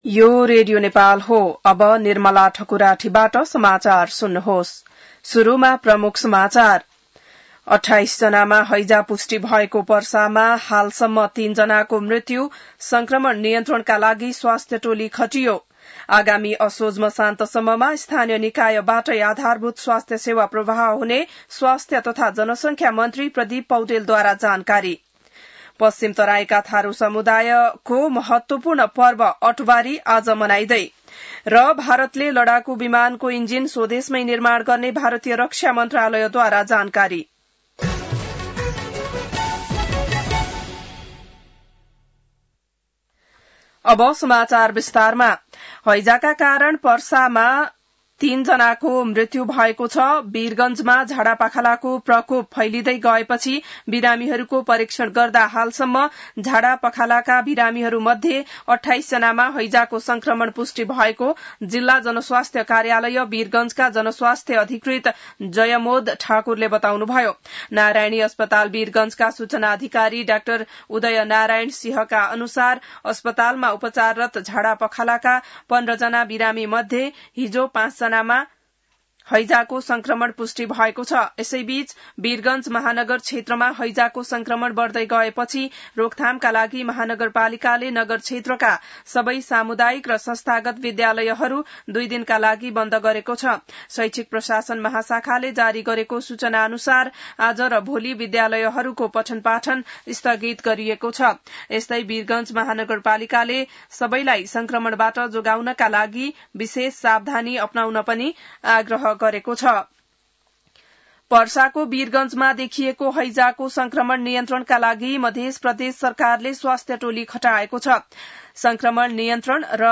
बिहान ९ बजेको नेपाली समाचार : ८ भदौ , २०८२